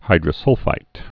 (hīdrə-sŭlfīt)